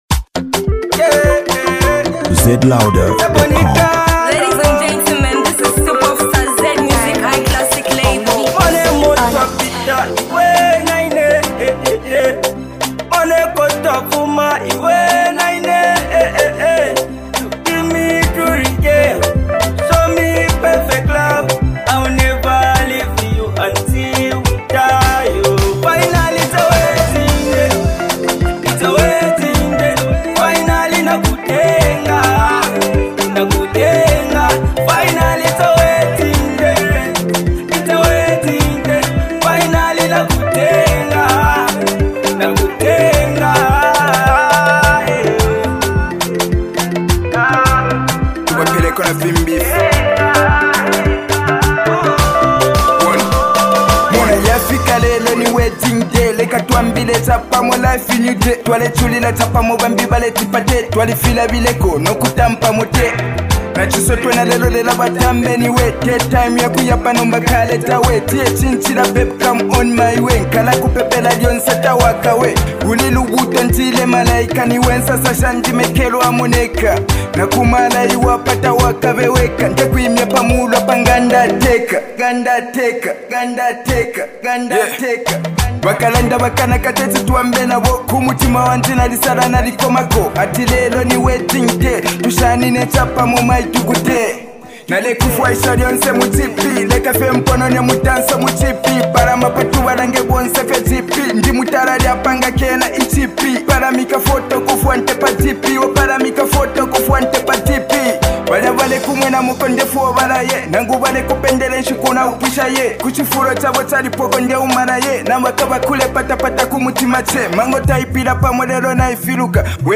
copperbelt music duo